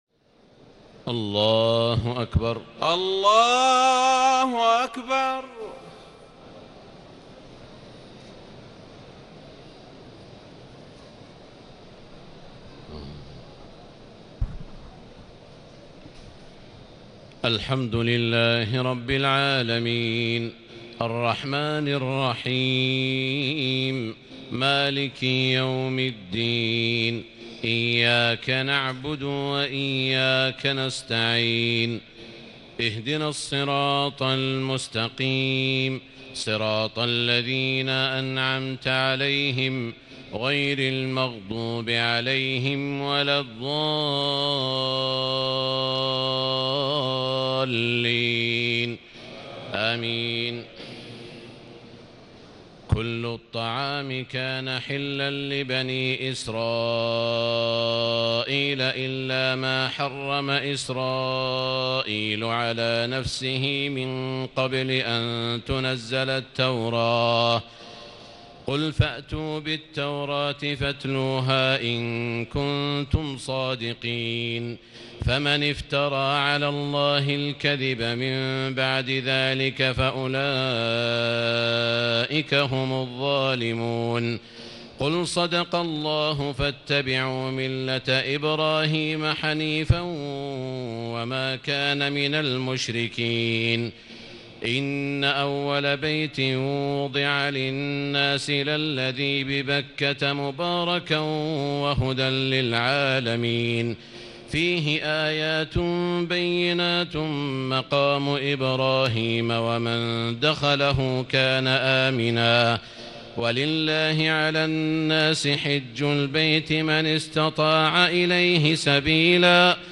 تهجد ليلة 24 رمضان 1439هـ من سورة آل عمران (93-185) Tahajjud 24 st night Ramadan 1439H from Surah Aal-i-Imraan > تراويح الحرم المكي عام 1439 🕋 > التراويح - تلاوات الحرمين